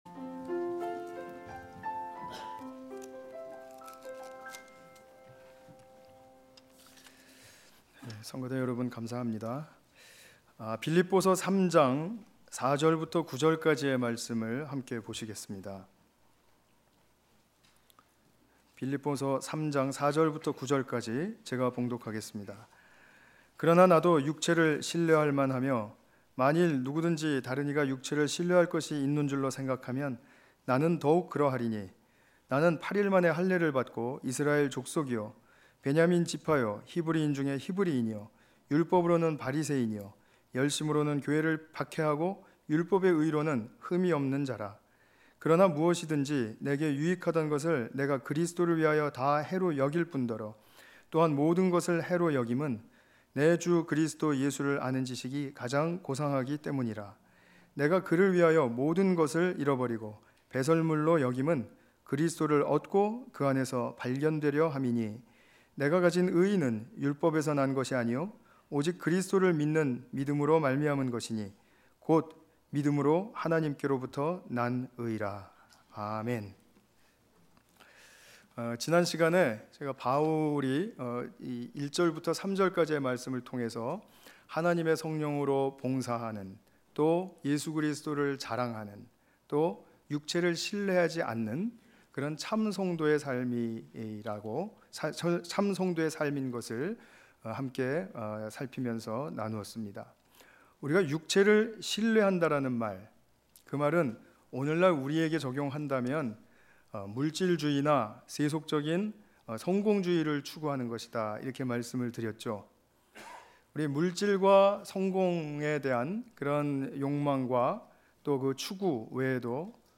빌립보서 3장 4 ~ 9절 관련 Tagged with 주일예배